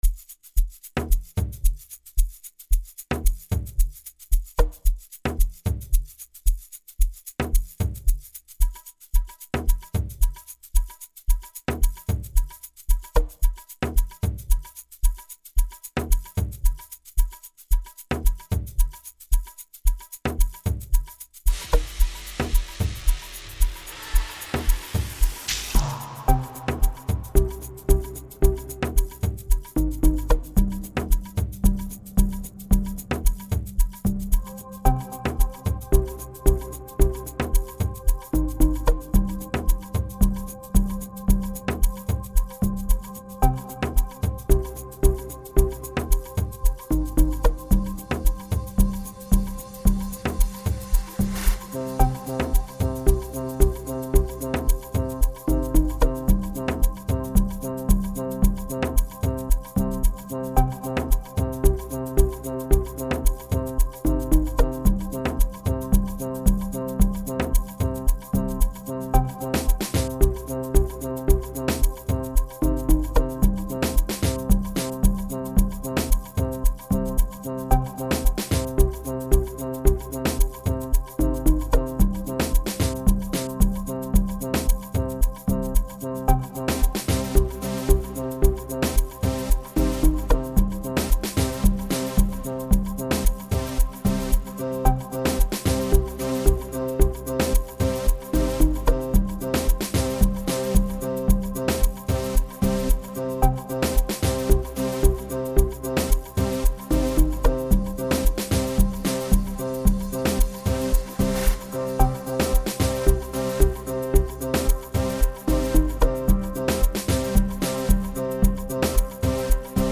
07:42 Genre : Amapiano Size